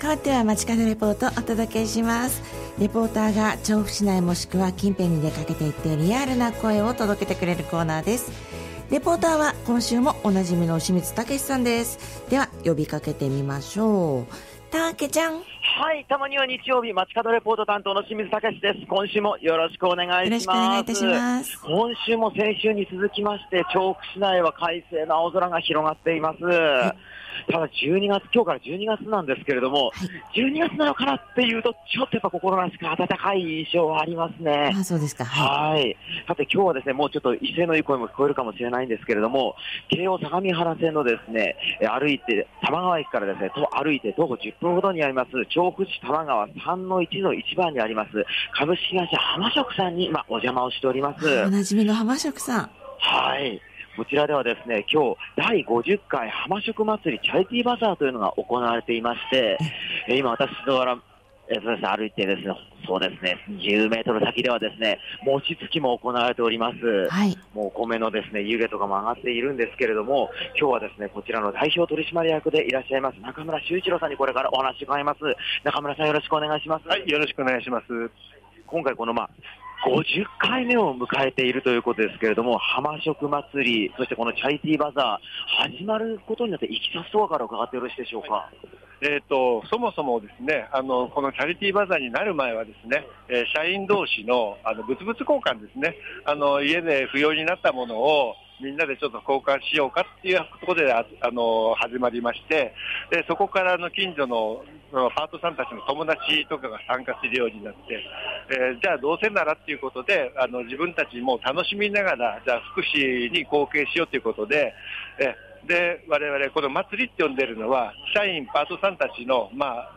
今週も快晴の下からお届けした本日の街角レポートは、
株式会社浜食さんで行われている「第50回浜食祭りチャリティーバザー」の会場からのレポートです！！